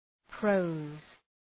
{prəʋz}